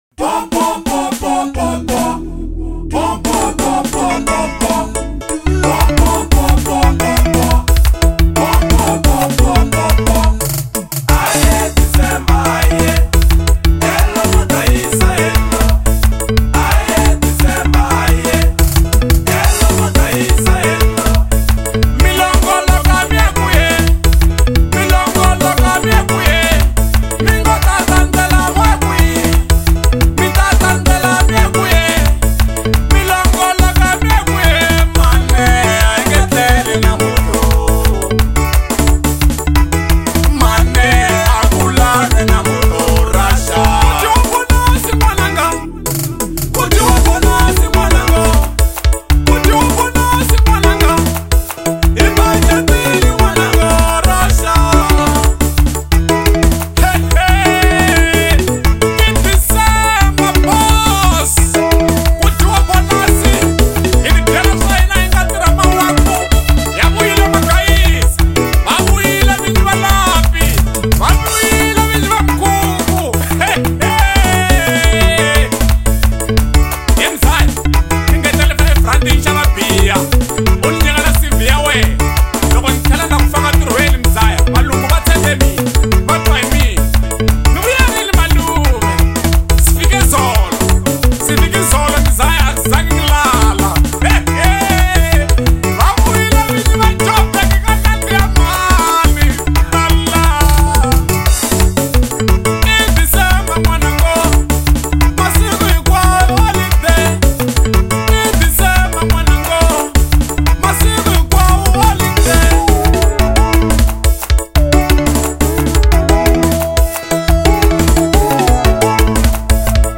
04:59 Genre : Xitsonga Size